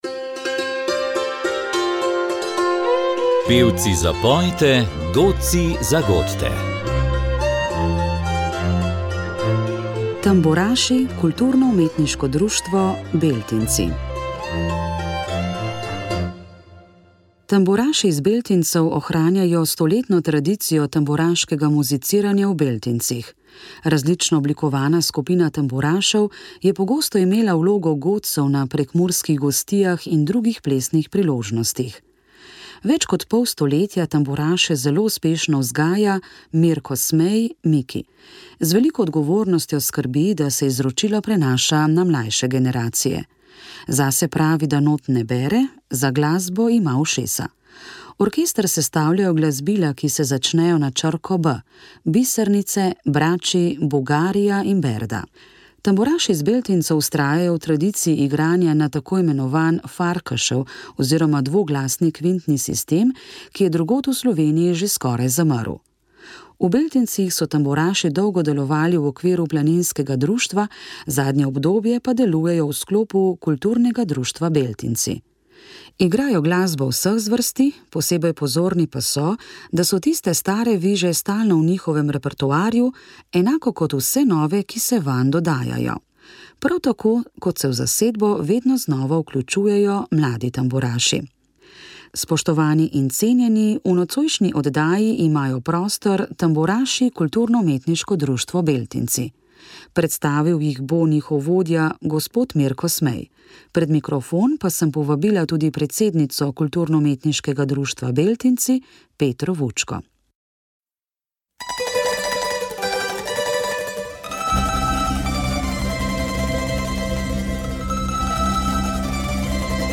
Skupina ljudskih pevk Korenine, Društvo podeželskih žena Gornjega Posočja, je 21. maja v Tolminu pripravila koncert ob 10. letnici delovanja. Pevke so se predstavile z večglasnim ubranim petjem in vsebinsko raznolikim repertoarjem
Odlomke s prireditve je prinesla tokratna oddaja o ljudski glasbi.